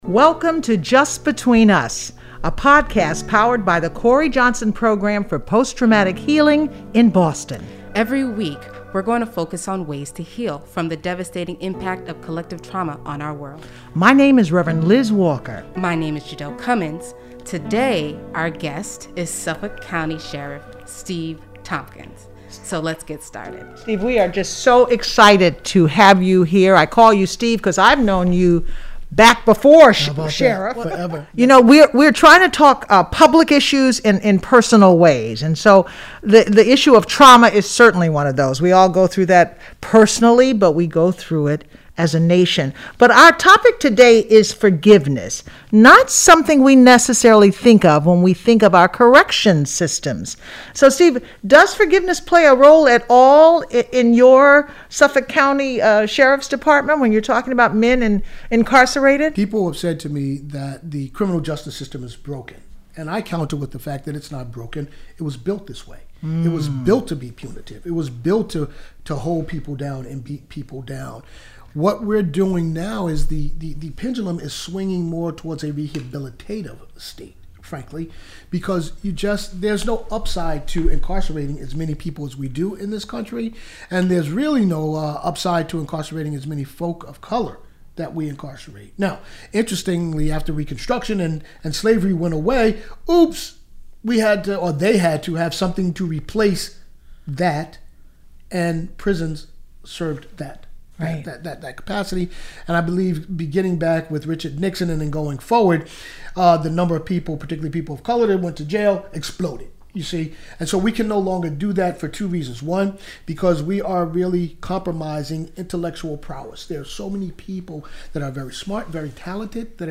This week, we talk with Suffolk County Sheriff, Steven Tompkins about the flaws of the criminal justice system and the power of forgiveness.